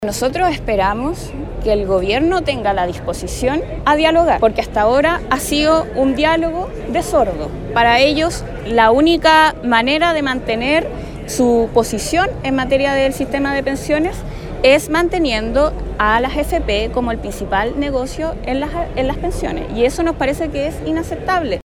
Desde el Frente Amplio, la diputada y presidenta de la comisión de Trabajo, Gael Yeomans, señaló que esperan que el Ejecutivo tenga la disposición a dialogar, criticando el hecho de que se mantengan a las AFP en el sistema previsional.
cuna-pensiones-gaelyeomans.mp3